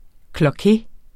Udtale [ klʌˈke ]